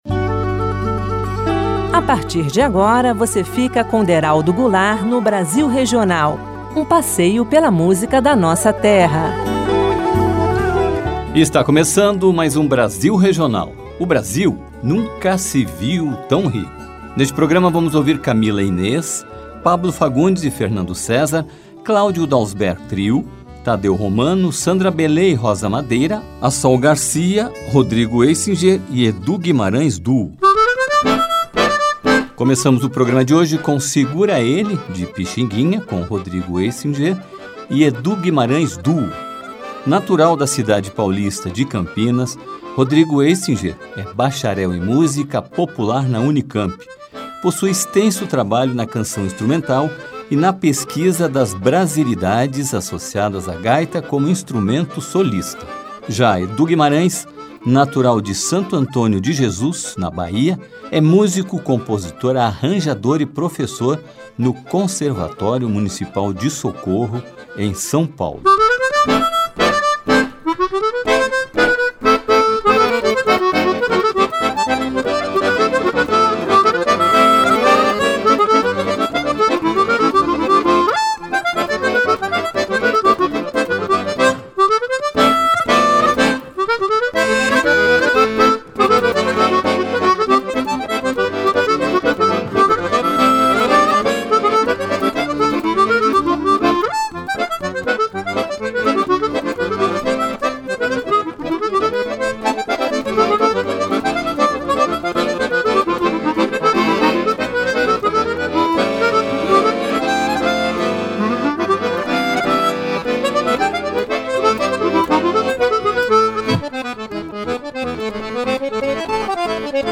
expressiva interpretação brasileira do Jazz-Bossa